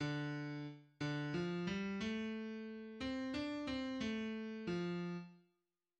{\clef bass \tempo 4=90 \time 3/4 \key bes\major \set Score.currentBarNumber = #1 \bar "" d4 r8 d f g a4. c'8 d' c' a4 f8. r16 r4 }\addlyrics {\set fontSize = #-2 - UN LIBSTU MIKH MIT VA- RER LI- BE } \midi{}